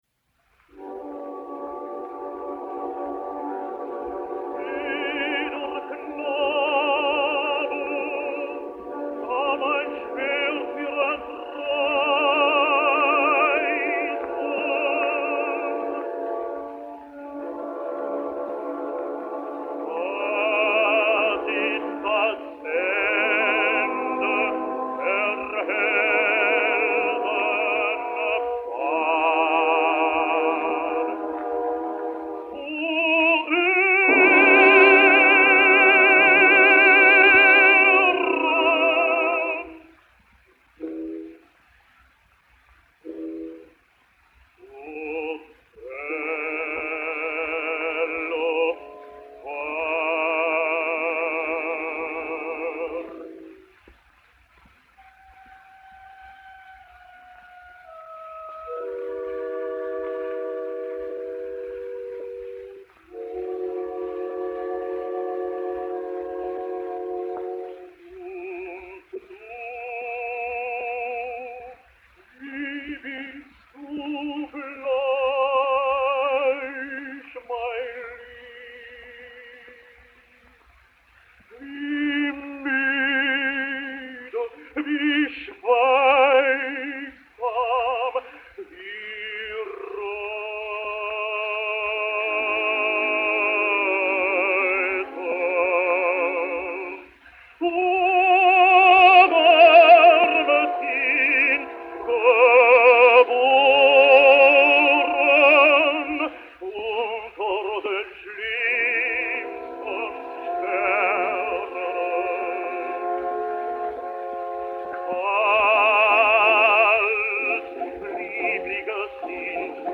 A soft voice, sad toned opening, the ring and dark baritonal sound of the voice, proclaim an ideal Otello voice.
The drama of the piece too, is beautifully captured, full of yearning and passion.
And no additional death noises to spoil or dignity of this genuinely noble portrayal